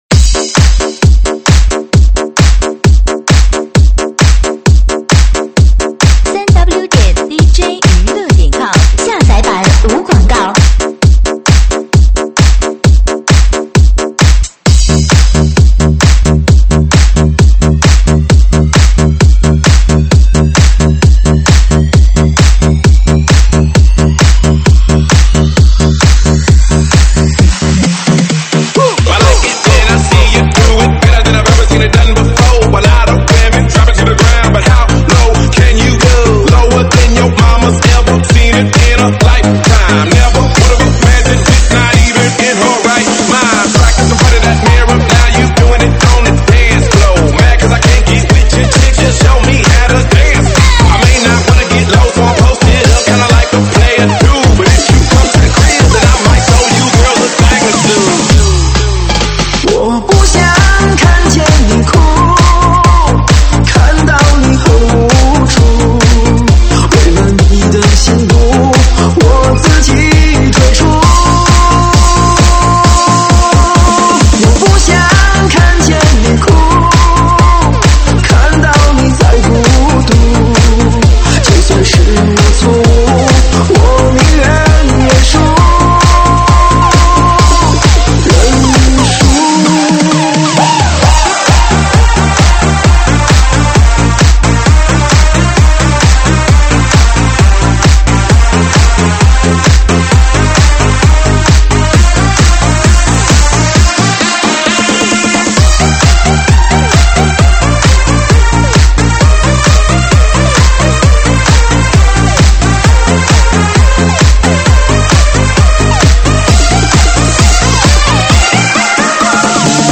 电子Electro